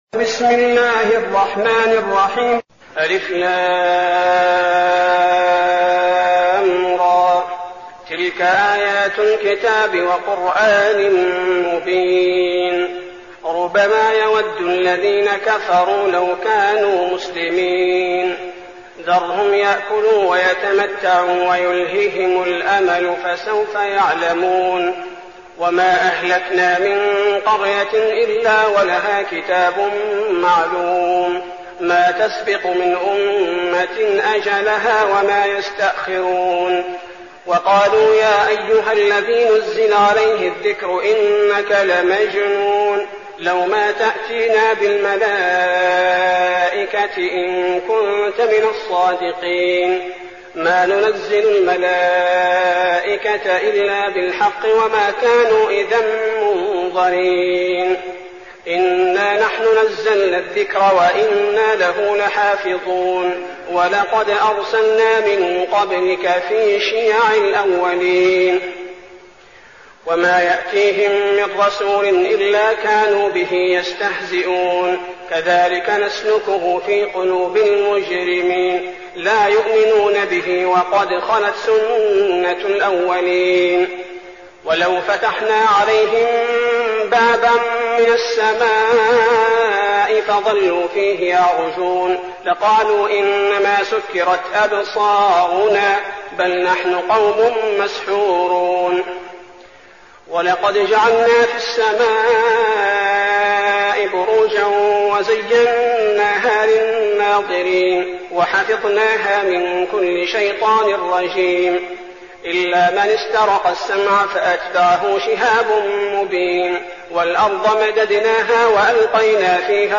المكان: المسجد النبوي الشيخ: فضيلة الشيخ عبدالباري الثبيتي فضيلة الشيخ عبدالباري الثبيتي الحجر